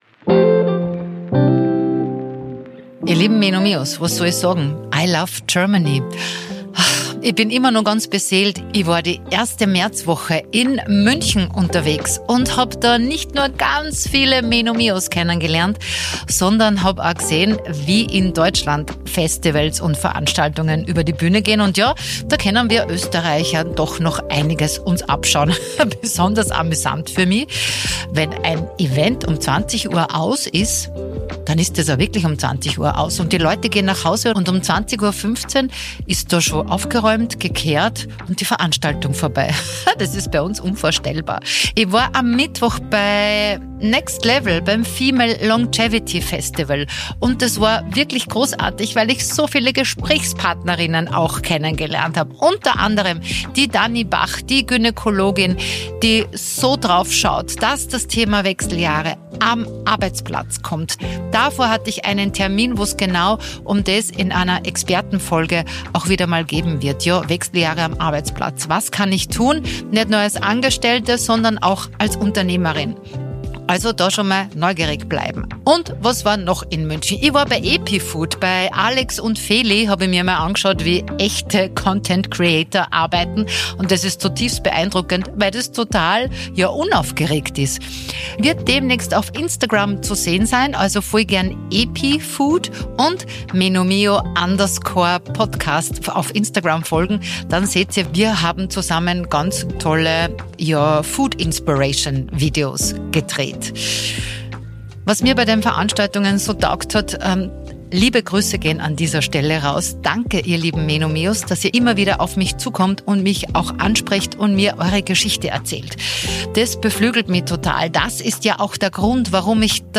Gemeinsam räumen sie mit dem Tabu der hormonellen Unterstützung auf und feiern das „wackere Welken“. Ildikó von Kürthy spricht gewohnt humorvoll, aber auch mit einer neuen, tiefgründigen Ernsthaftigkeit über ihr persönliches Älterwerden.
Ein ehrliches Gespräch auf Augenhöhe – ohne Drama, dafür mit viel Klarheit, Herz und dem typischen MENOMIO-Glitzer.